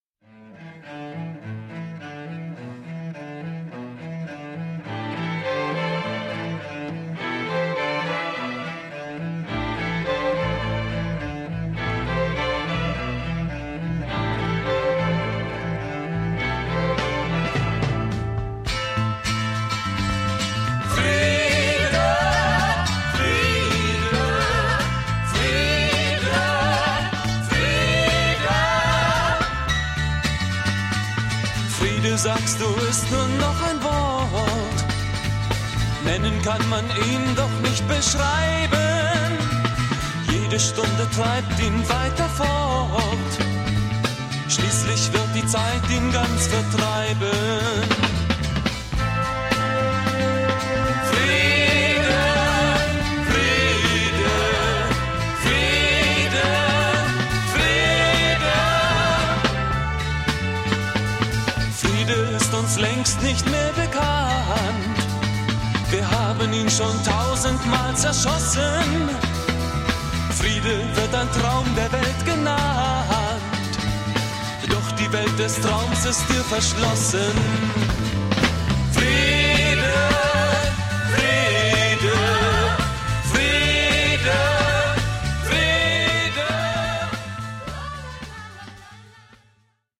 Aufgenommen wurde die LP 1971.
Saubere Aufnahme, Produktion und Instrumentalisierung.